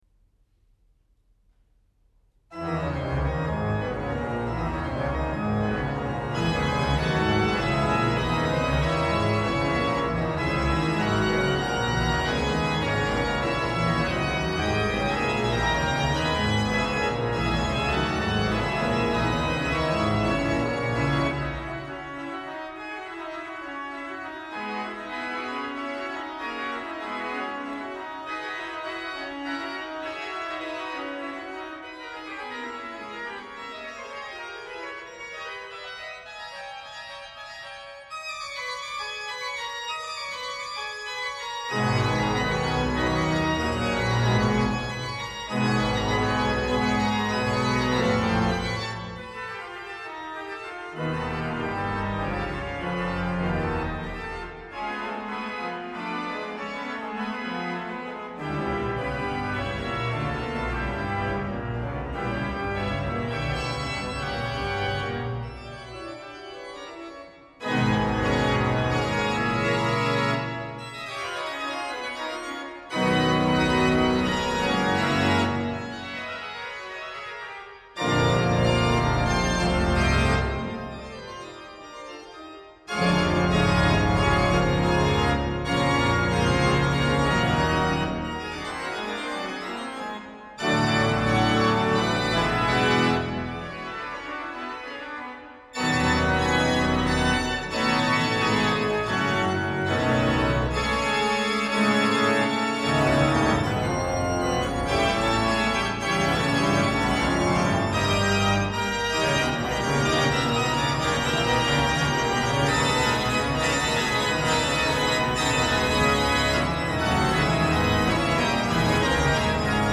Un dels moments més originals és el postludium d’orgue sol, d’una força extraordinària. Aquí el podeu escoltar enllaçat amb l’Exode orquestral final.
És una interpretació electritzant que espero que us entusiasmi tant com en a mi.
òrgan
Berliner Philharmoniker
Direcció musical: Sir Simon Rattle
Philharmonie Berlin, 8 de setembre de 2013